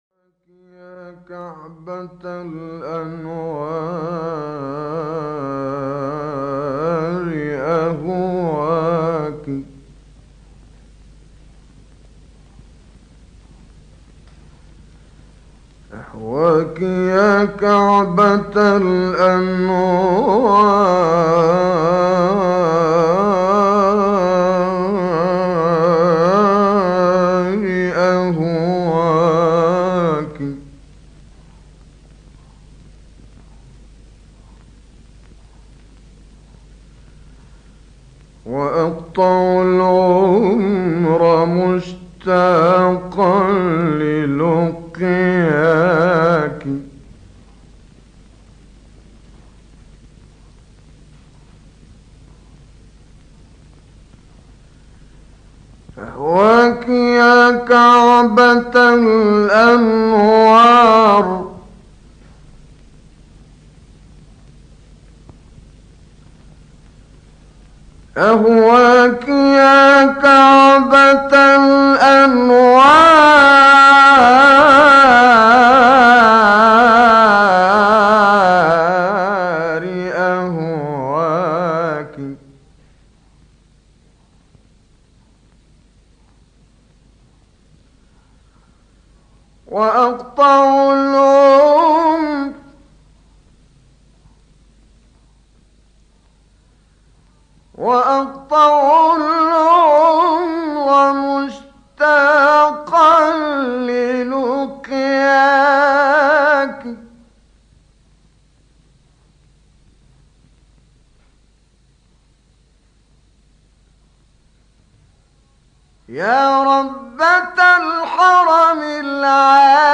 کانون خبرنگاران نبأ: محمد زکی یوسف مشهور به کامل یوسف البهتیمی، قاری و منشد مصری در سال‌های ۱۳۰۱ تا ۱۳۴۸ هجری شمسی می‌زیسته‌ است؛ابتهال «أهواکى یا کعبة الأنوار» و «فوادی» از معروف‌ترین کارهای وی است که توسط بخش چند رسانه‌ای نبأ ارائه می‌شود.
ابتهال أهواکى یا کعبة الأنوار با صدای استاد کامل یوسف البهتیمی